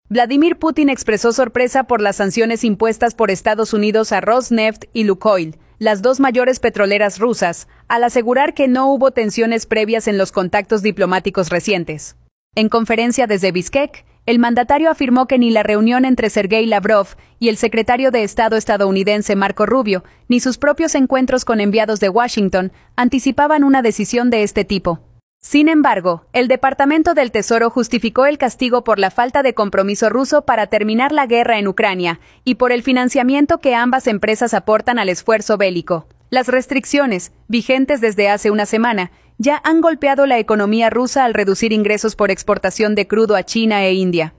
audio Vladímir Putin expresó sorpresa por las sanciones impuestas por Estados Unidos a Rosneft y Lukoil, las dos mayores petroleras rusas, al asegurar que no hubo tensiones previas en los contactos diplomáticos recientes. En conferencia desde Biskek, el mandatario afirmó que ni la reunión entre Serguéi Lavrov y el secretario de Estado estadounidense Marco Rubio, ni sus propios encuentros con enviados de Washington, anticipaban una decisión de este tipo.